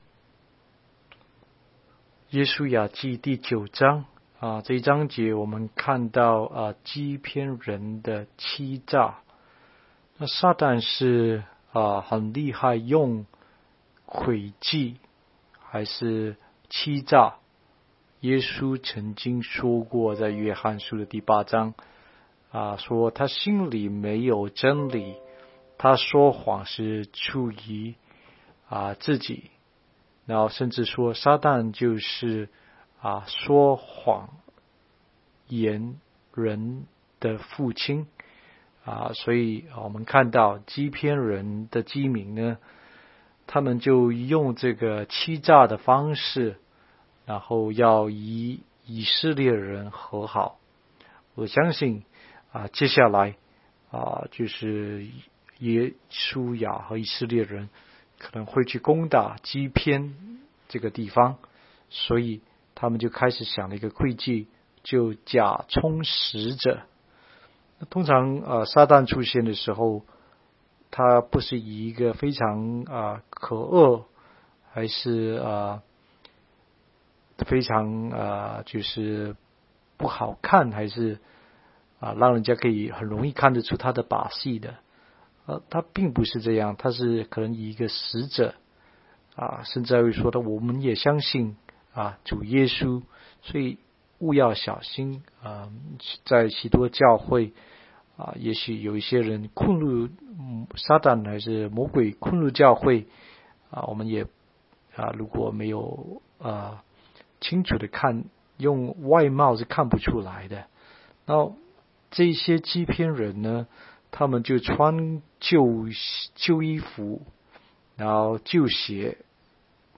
16街讲道录音 - 每日读经-《约书亚记》9章